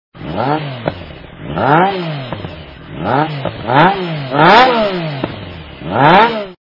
» Звуки » Авто, мото » Рев - двигателя
При прослушивании Рев - двигателя качество понижено и присутствуют гудки.
Звук Рев - двигателя